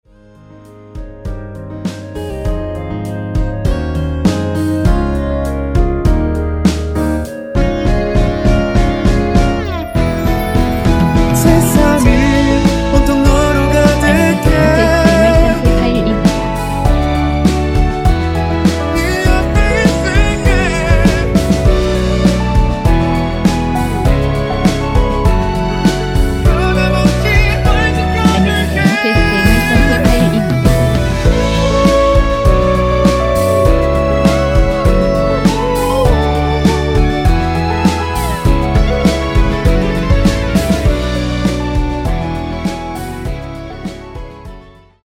이곡의 코러스는 미리듣기 부분이 전부 입니다.
원키에서(-1)내린 멜로디와 코러스 포함된 MR입니다.
Ab
앞부분30초, 뒷부분30초씩 편집해서 올려 드리고 있습니다.
중간에 음이 끈어지고 다시 나오는 이유는